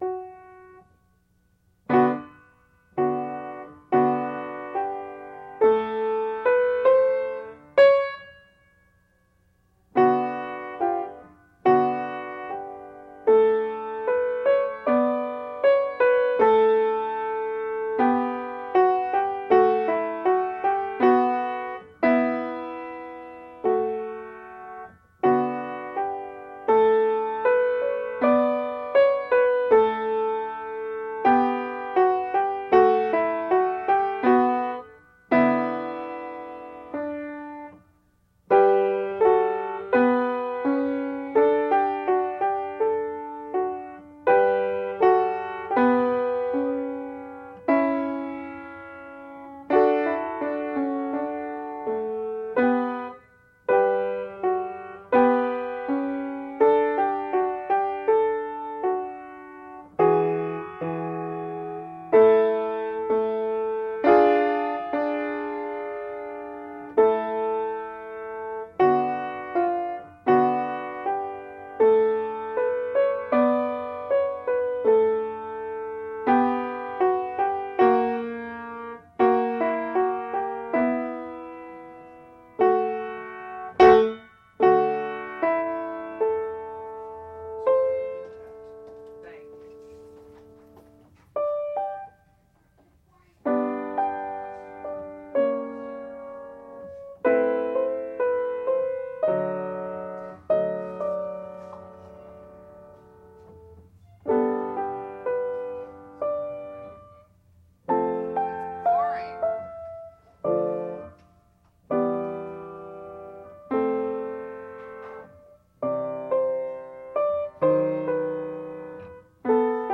Звуки пианино
Звук ученика, пытающегося играть на фортепиано